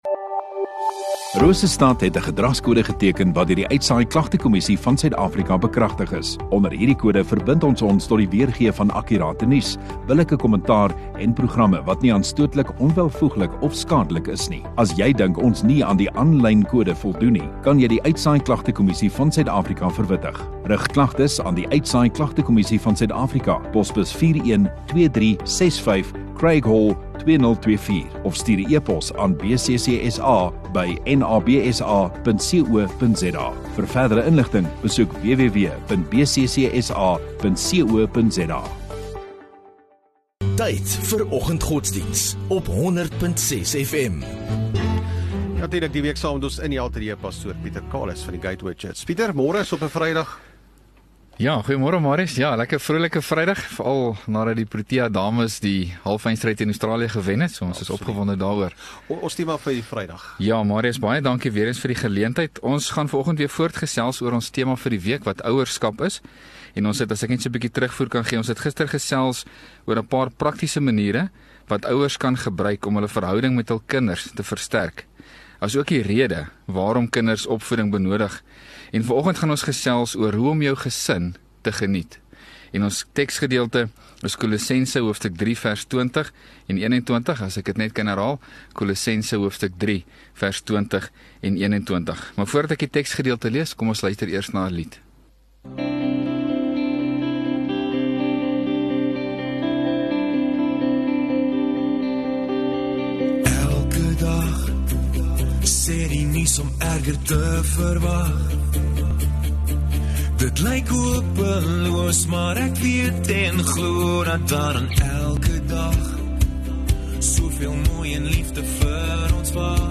31 Jan Vrydag Oggenddiens